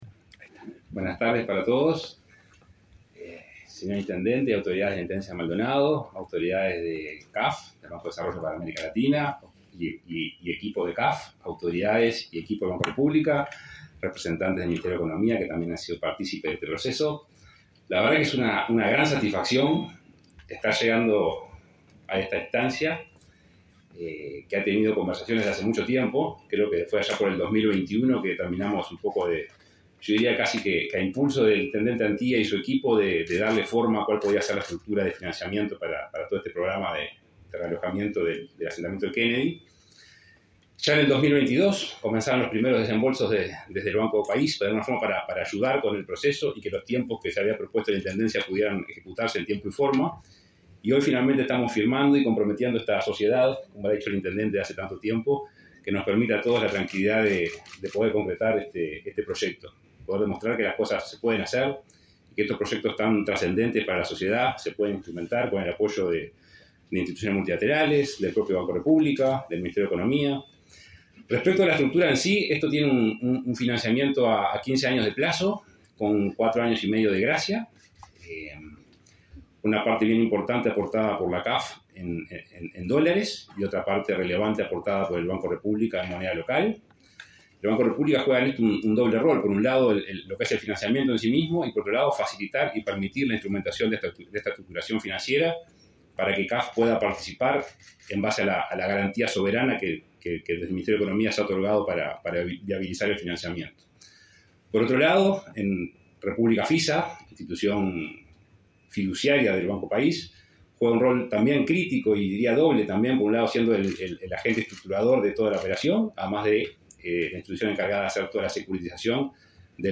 Palabras del presidente del BROU, Salvador Ferrer
En el evento realizó declaraciones el titular del BROU, Salvador Ferrer.